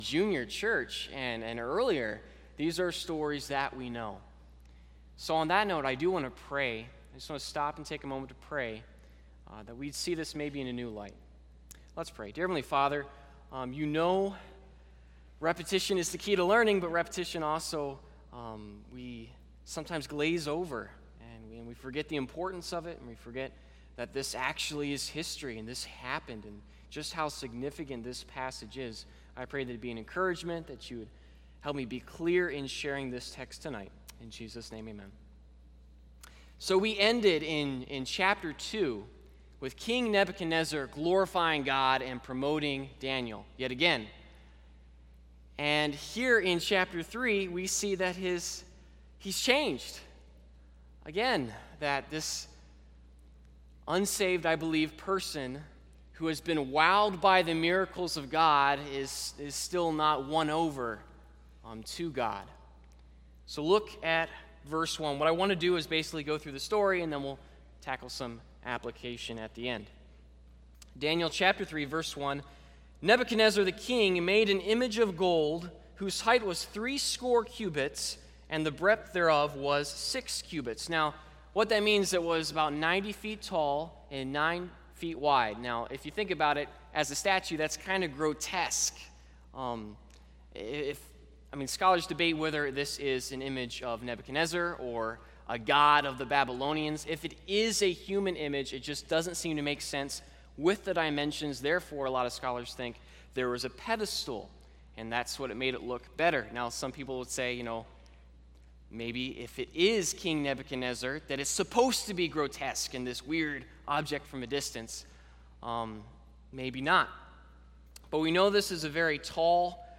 Service Type: Sunday Evening Topics: Faithfulness , Loyalty , Peer-pressure , Teens